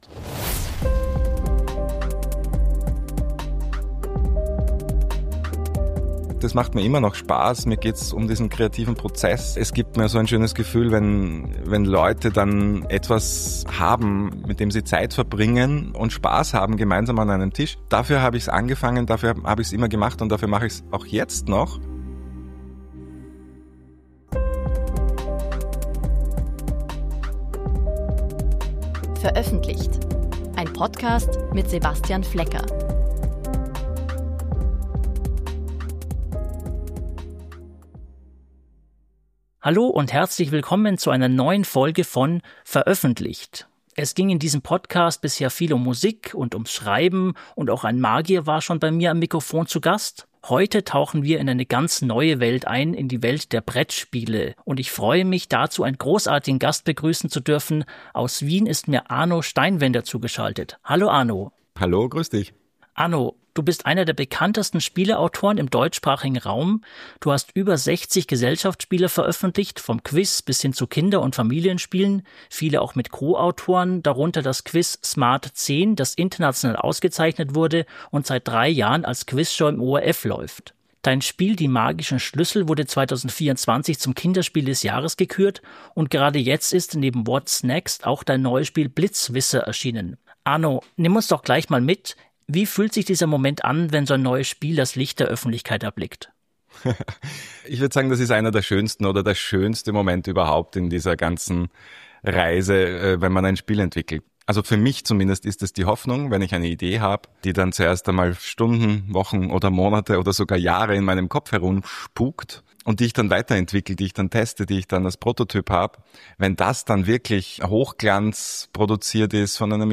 Ein Gespräch über den langen Weg vom Prototyp bis zum fertigen Brettspiel – und dem großen Erfolg von "Smart 10"